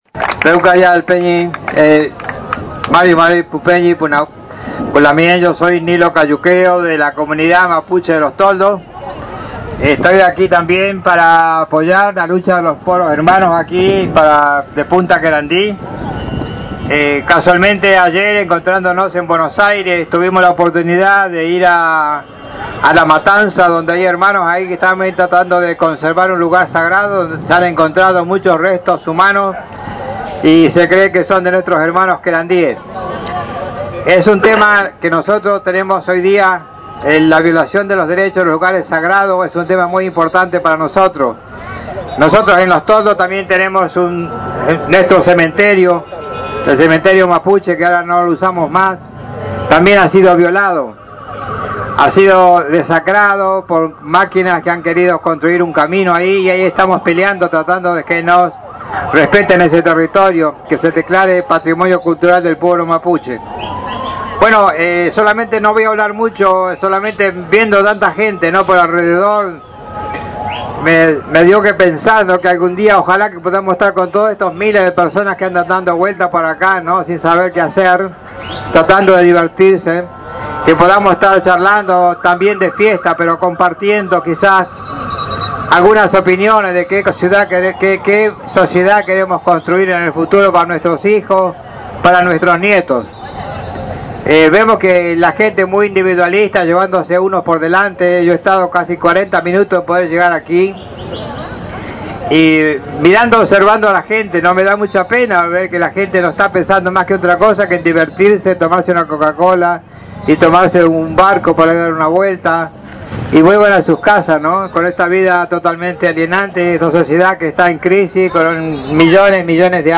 compartió su mirada en el gran acto de los Pueblos Originarios que se desarrolló en el centro de Tigre